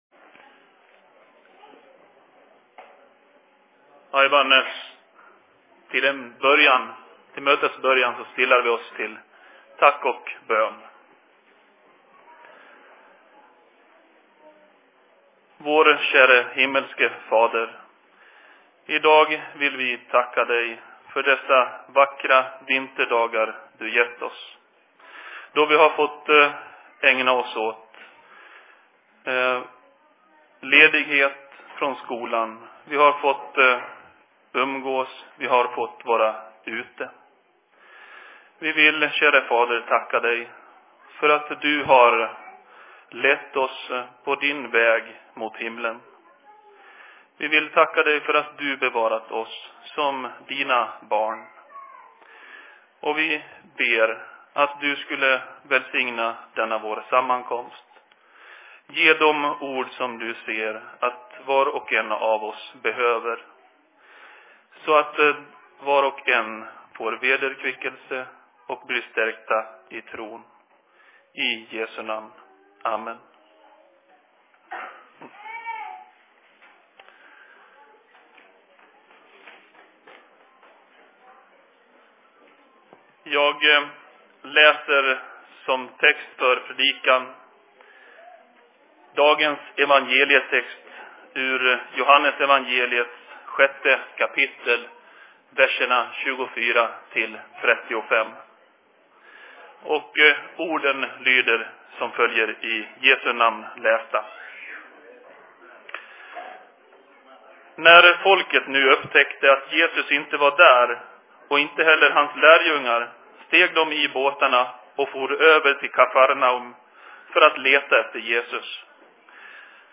Se Predikan I Dalarnas Fridsförening 06.03.2016
Plats: SFC Dalarna